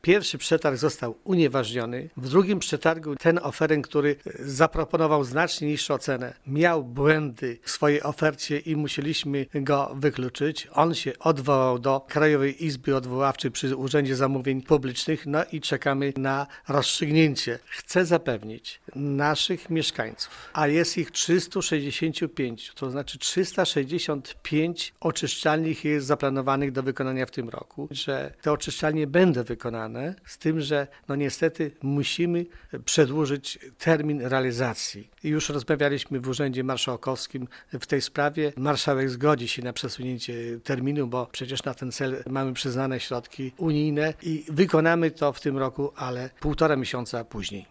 Zaplanowane na ten rok prace rozpoczną się nieco później, ale na pewno zostaną wykonane – zapewnia zastępca wójta Wiktor Osik. Tłumaczy, że przesunięcie terminu jest związane z wydłużeniem procedury przetargowej: